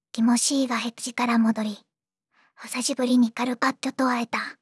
voicevox-voice-corpus / ROHAN-corpus /ずんだもん_ヒソヒソ /ROHAN4600_0017.wav